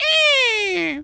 pgs/Assets/Audio/Animal_Impersonations/bird_large_squawk_01.wav at master
bird_large_squawk_01.wav